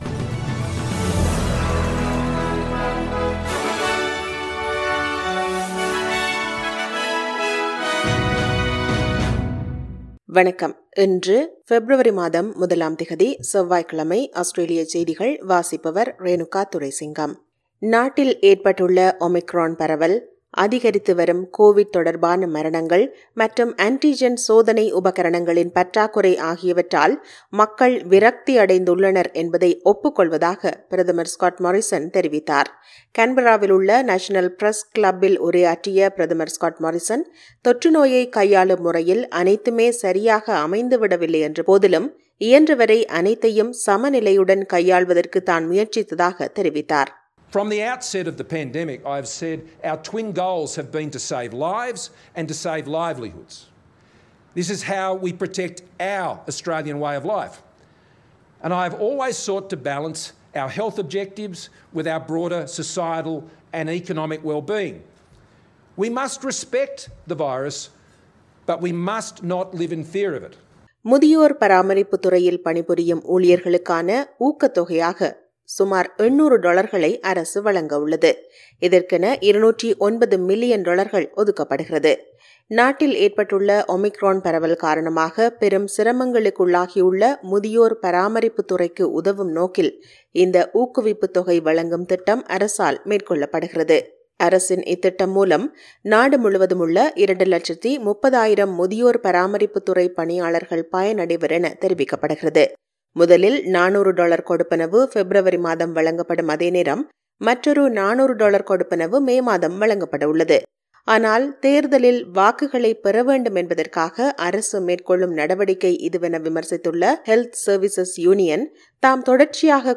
Australian news bulletin for Tuesday 01 Feb 2022.